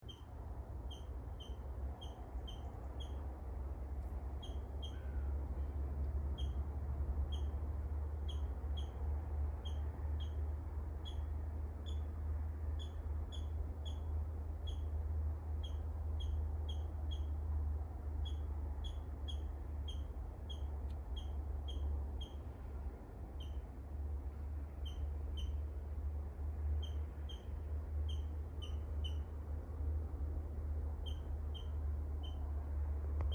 Putns (nenoteikts), Aves sp.
Administratīvā teritorijaValkas novads
СтатусСлышен голос, крики